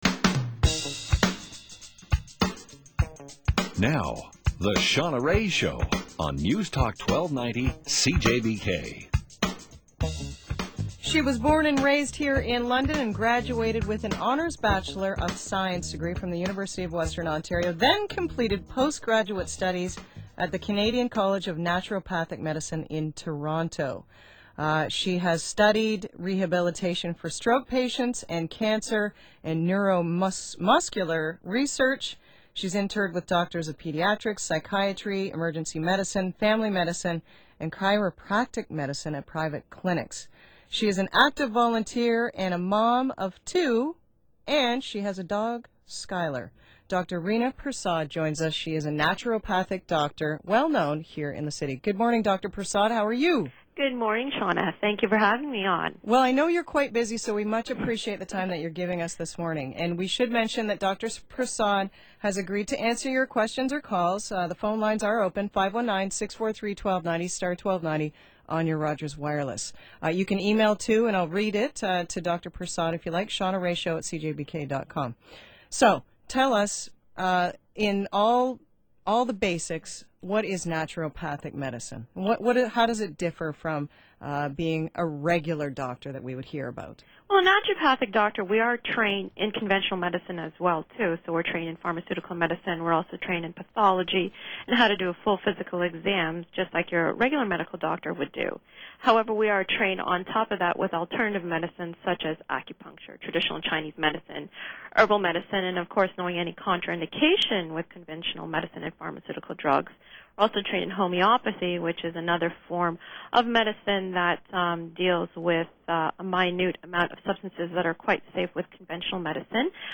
live radio interview